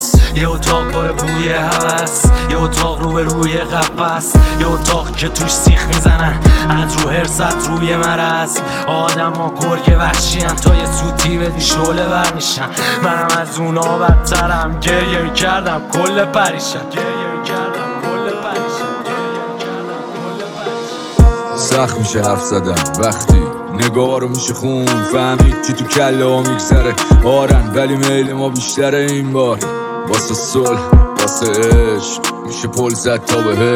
ریمیکس جدید رپ